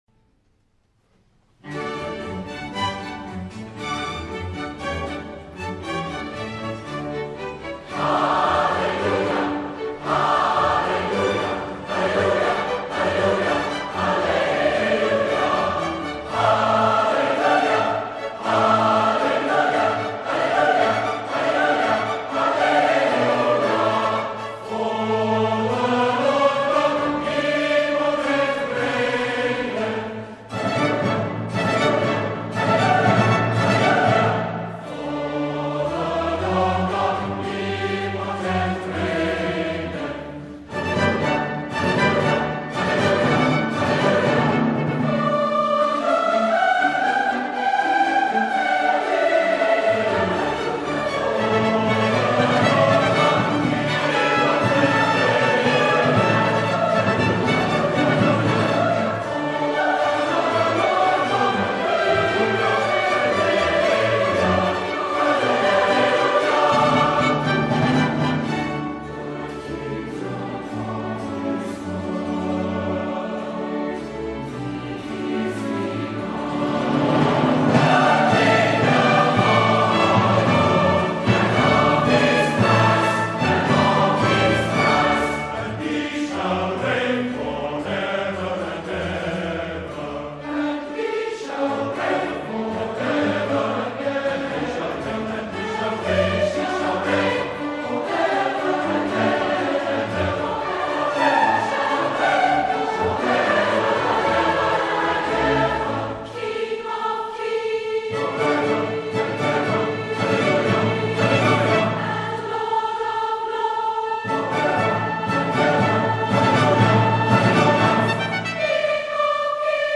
live at the Sydney Opera House